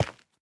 Minecraft Version Minecraft Version 1.21.5 Latest Release | Latest Snapshot 1.21.5 / assets / minecraft / sounds / block / dripstone / step5.ogg Compare With Compare With Latest Release | Latest Snapshot